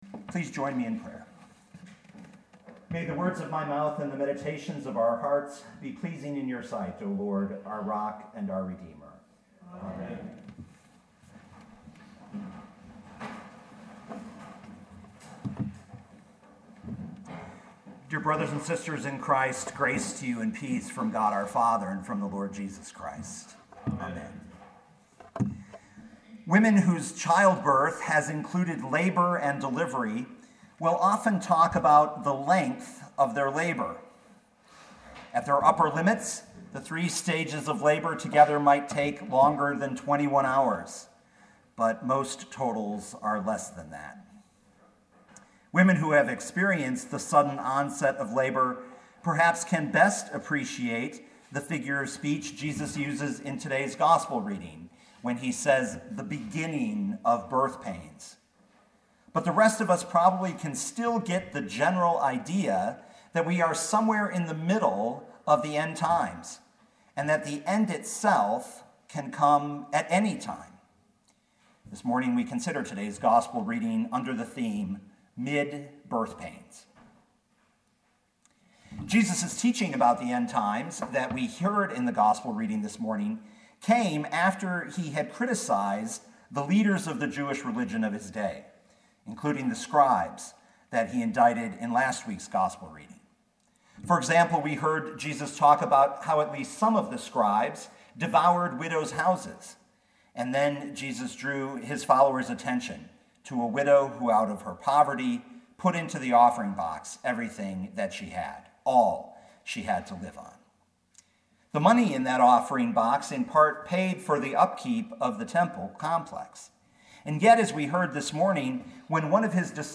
2018 Mark 13:1-13 Listen to the sermon with the player below, or, download the audio.